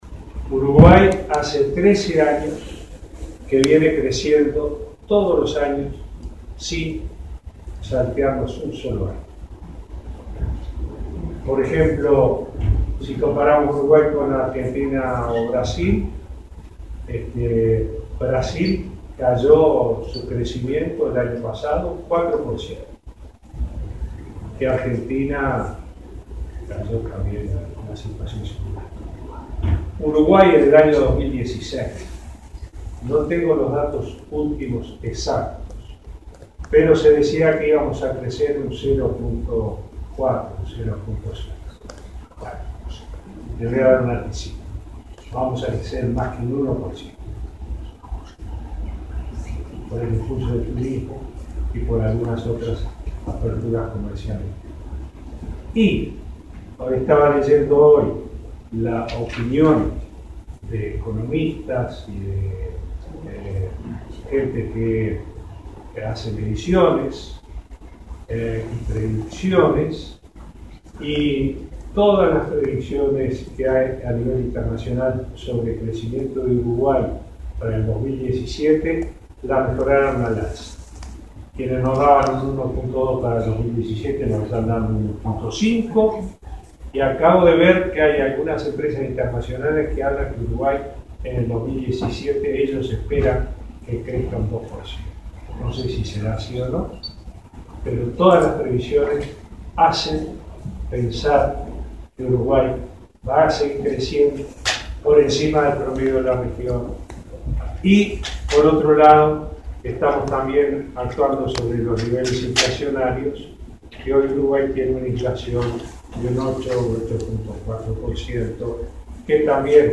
Ante empresarios y uruguayos residentes en ese país, dijo que los especialistas aseguran que se controlará la inflación y que disminuiría a menos de 8 puntos.